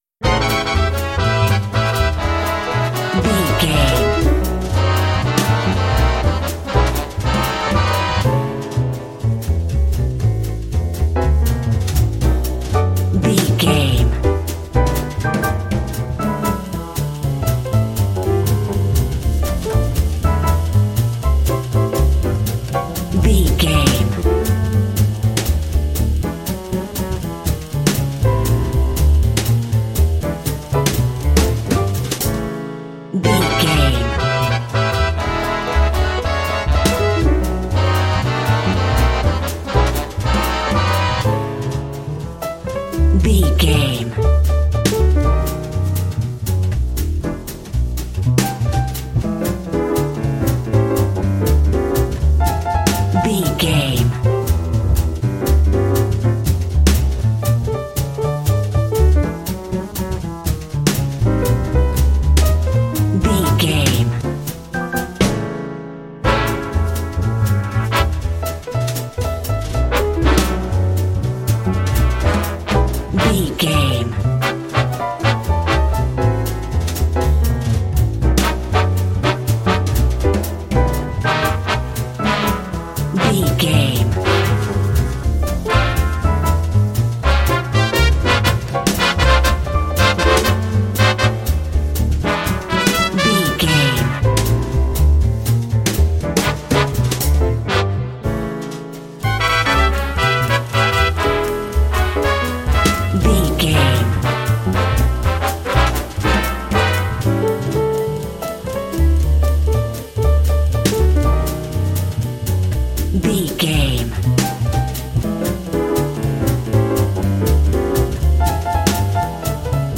Ionian/Major
Fast
energetic
driving
groovy
piano
electric guitar
brass
drums
double bass
bebop swing
jazz